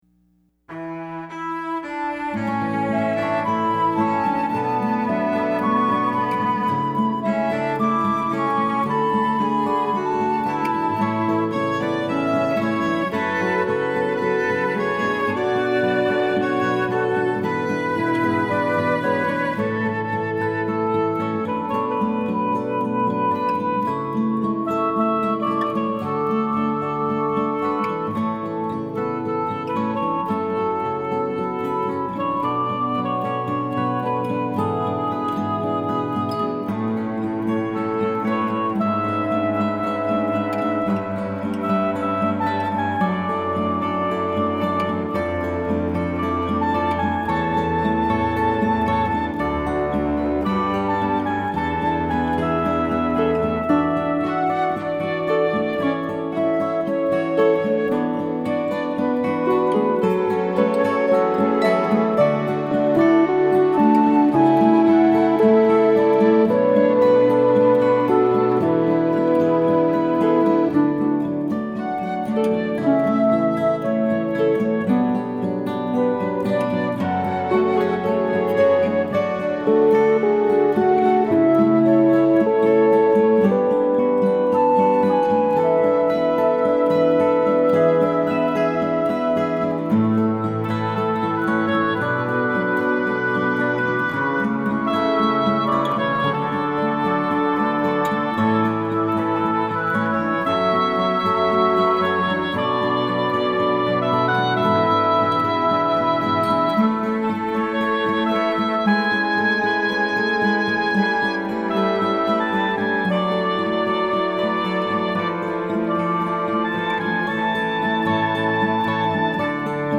Let these instrumentals take you on a musical journey of healing with their touching melodies.
Initially, I worked with an arranger to create these lush arrangements. Later on, I gravitated more to acoustic guitar, piano and piano, and solo piano.